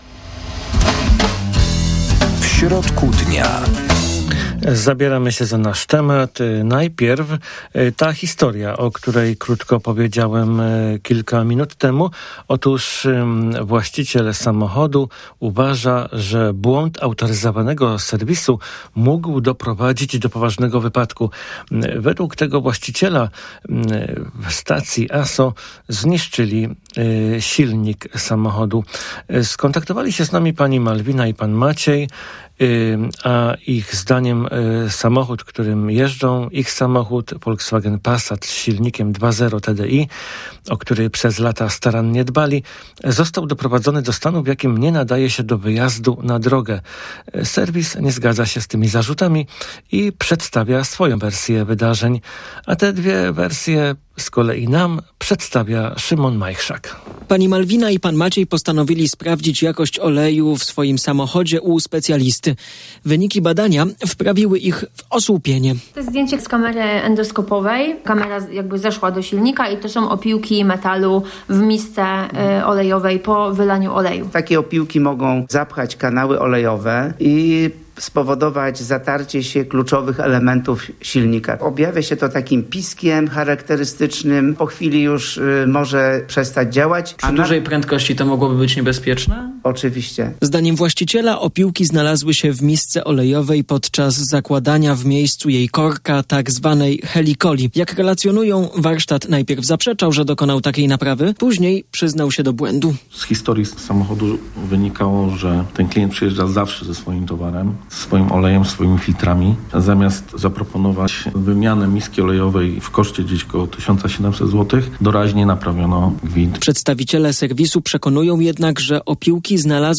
Zapraszamy do rozmowy w audycji „W środku dnia”.